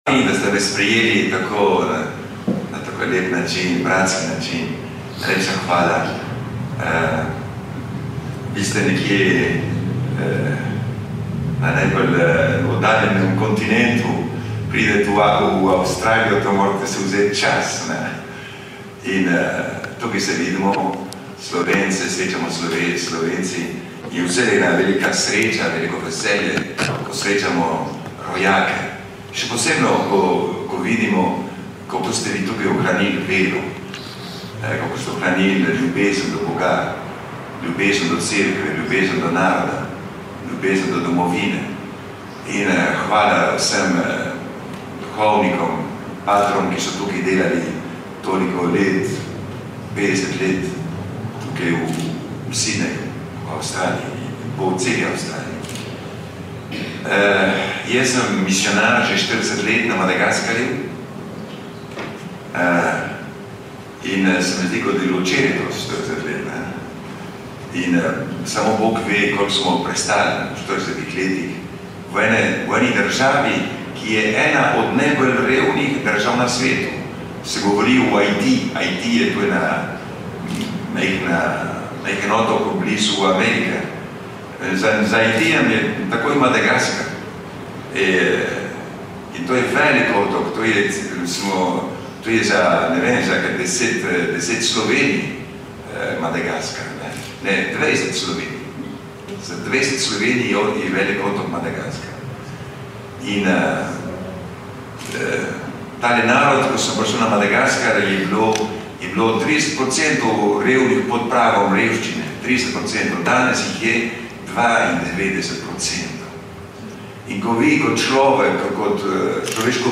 Misijonar Pedro opeka med pridigo Poslušajte pridigo v mp3/windows media player/